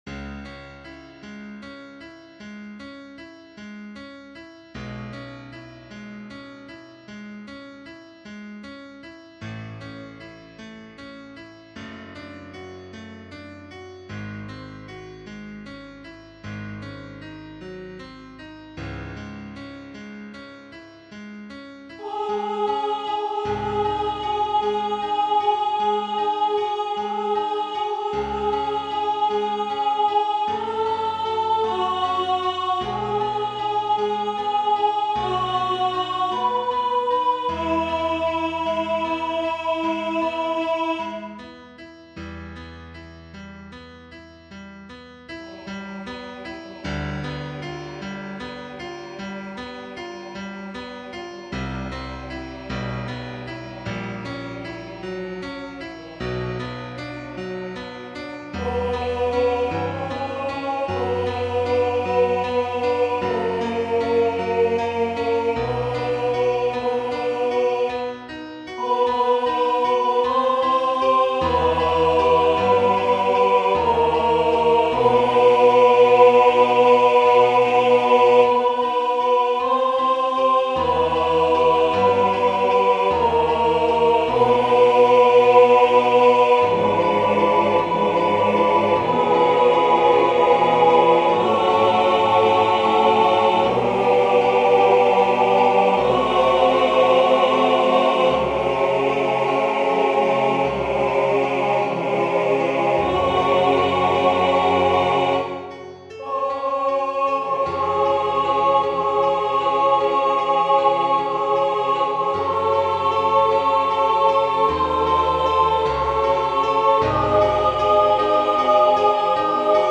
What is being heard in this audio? SATB Voicing/Instrumentation: SATB